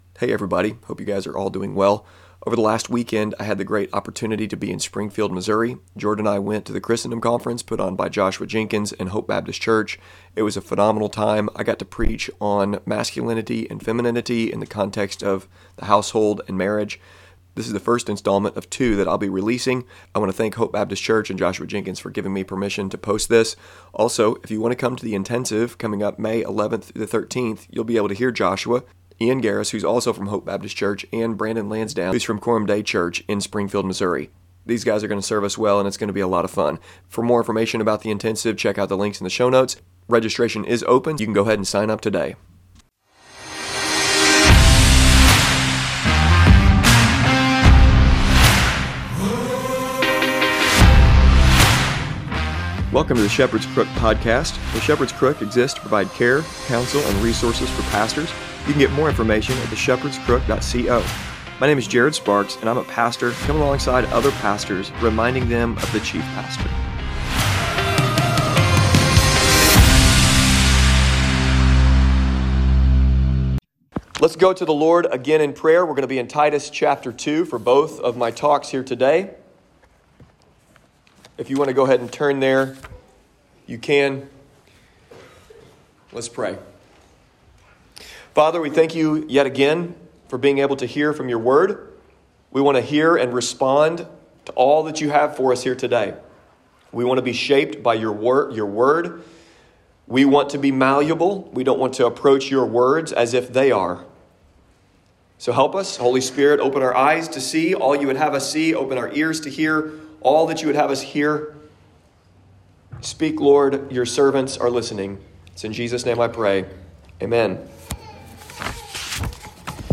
I am releasing, with permission, a sermon I preached at the Hope Baptist Church Christendom Conference.